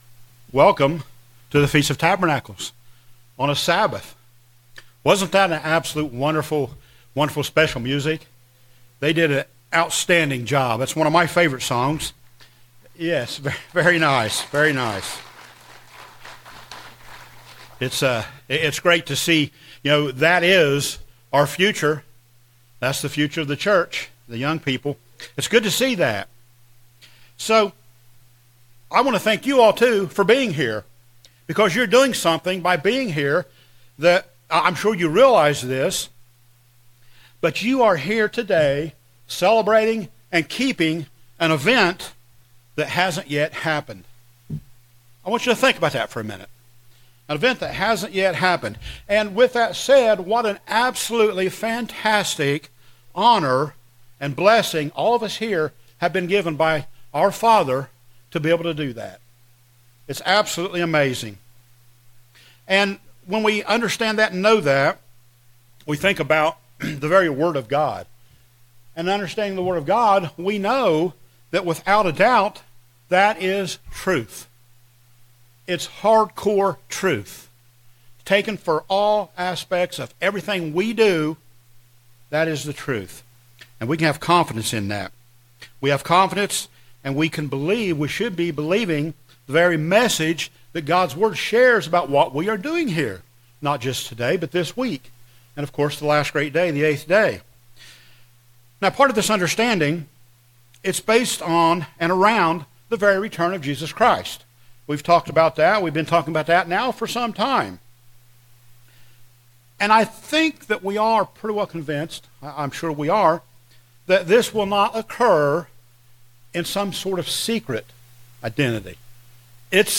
Given in Daytona Beach, Florida